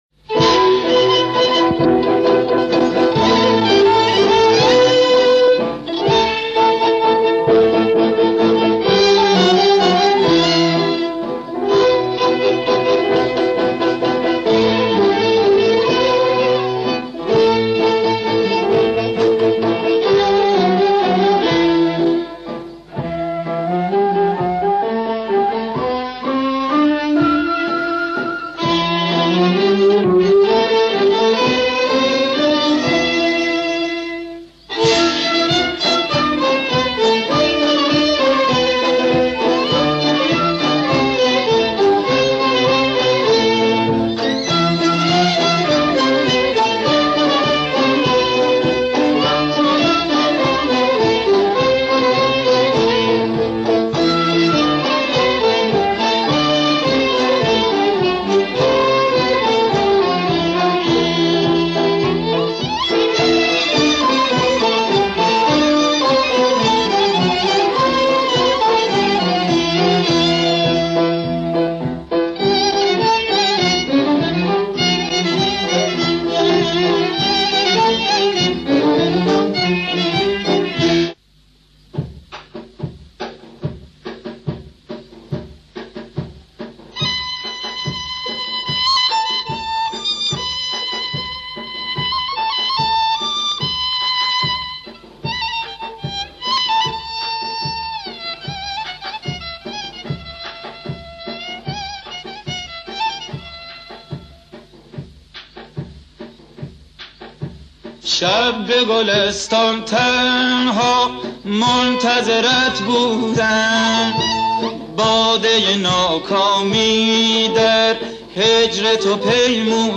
** موسيقي ملّي و سنتي **